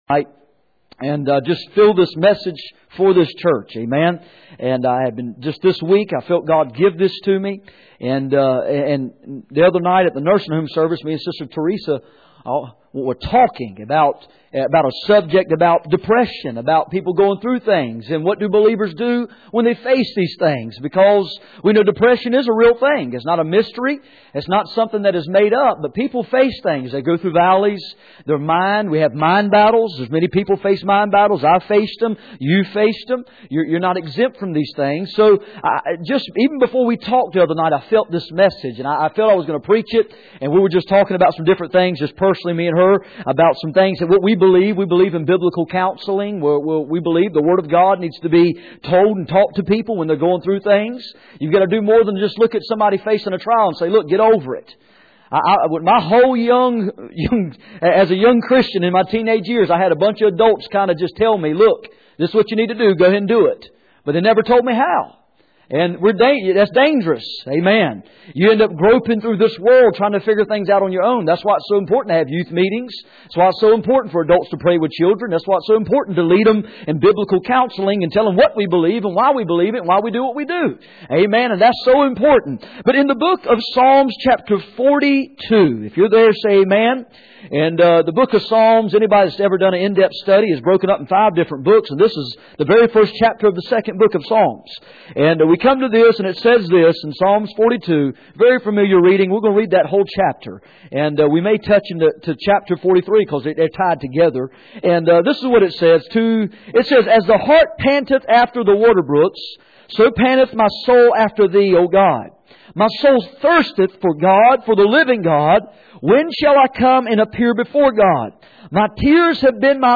Passage: Psalm 42:1-11 Service Type: Sunday Evening